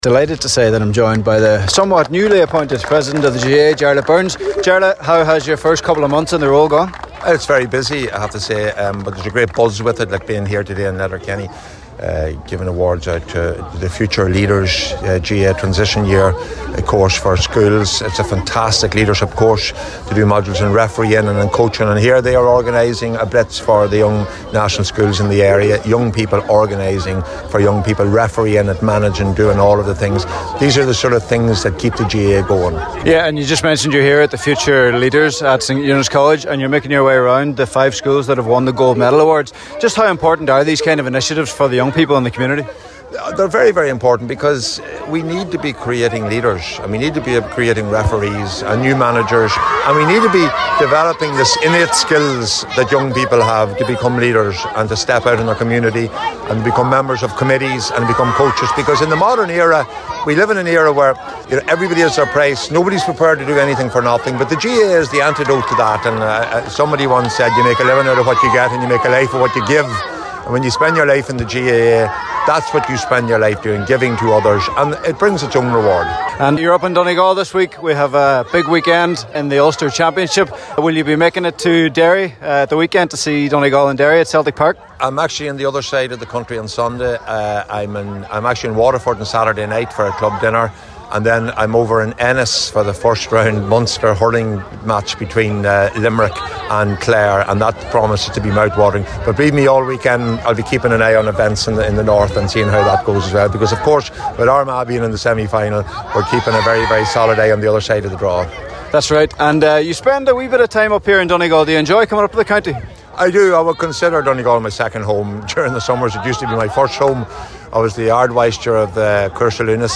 caught up with GAA President Jarlath Burns at the event…